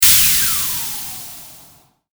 戦闘 （163件）
発射2.mp3